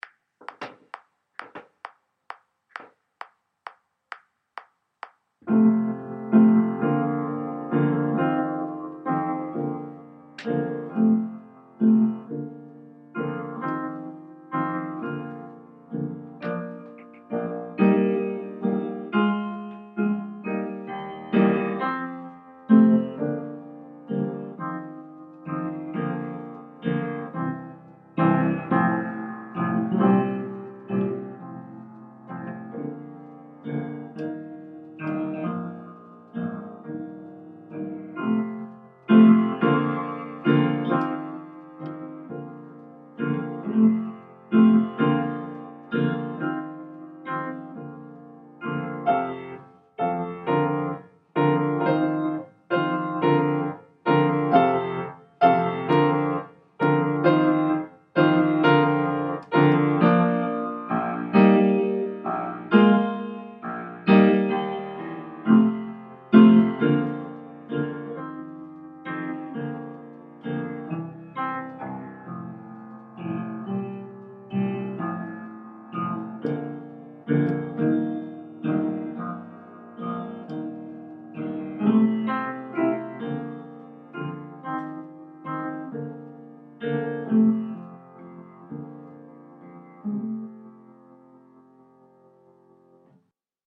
Slow Boogie Piano Only